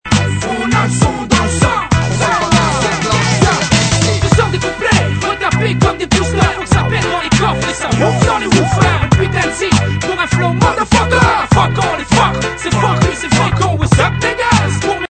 rap